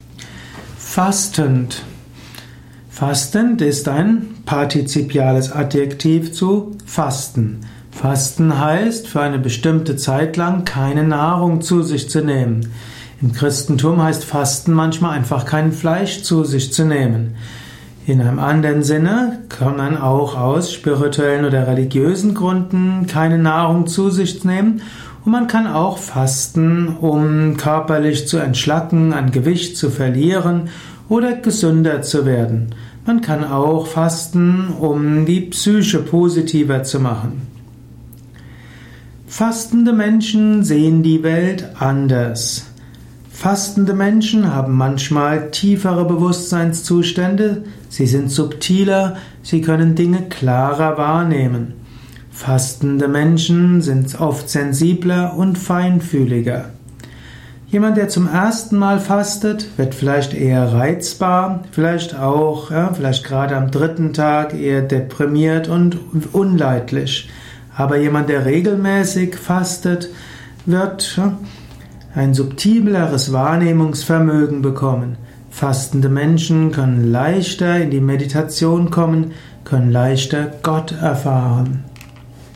Ein Kurzvortrag über die Frage, wie man fastend bewusst leben kann.